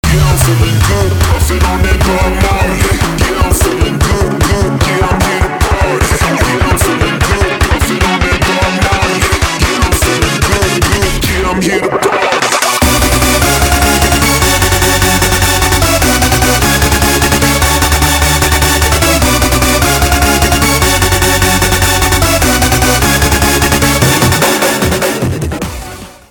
• Качество: 192, Stereo
electro
ритмичный трек.